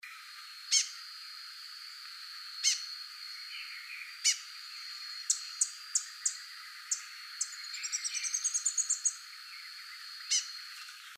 Saíra-de-Papo-Preto (Hemithraupisguira)
O mais curioso é que esses bandos se movimentam tão rápido entre as copas que parece que a mata sussurra — e quem escuta com atenção logo nota aquele “tssiptssiptssip…” da saíra, como se ela fosse uma guia que avisa: "Tem comida aqui!” ou seja, ela é uma líder discreta e elegante, sempre em movimento, ajudando a manter a harmonia entre espécies diferentes.